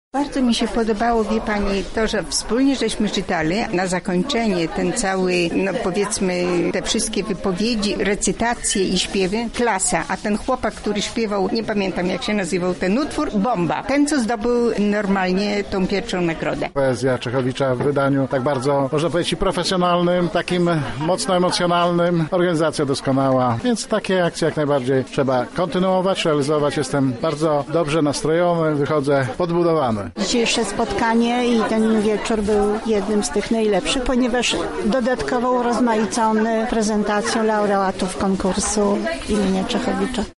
Relacja z wydarzenia